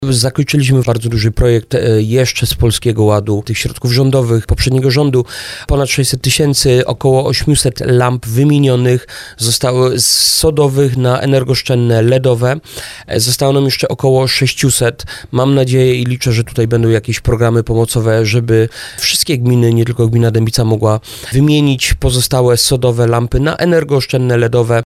Na terenie gminy Dębica wymieniono 800 lamp – mówi wójt gminy Dębica Adam Pieniążek.